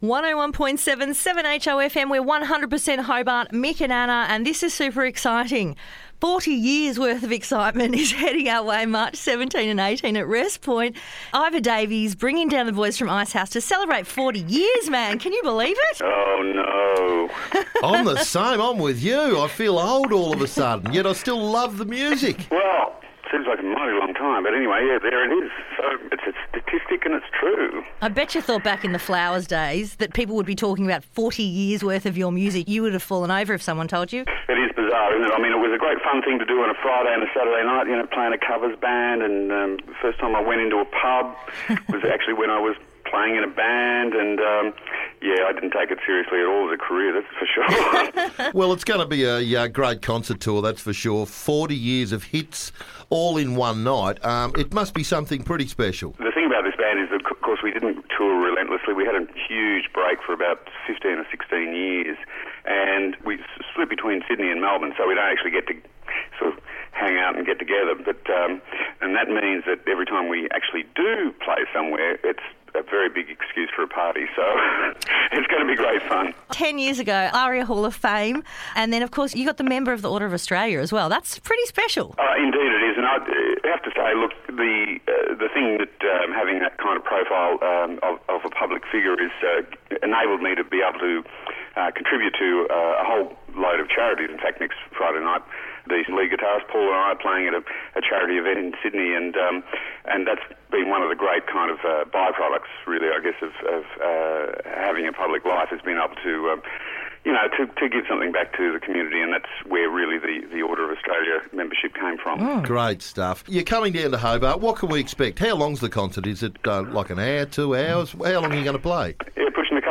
had a chat with lead singer Iva Davies